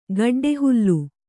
♪ gaḍḍe hullu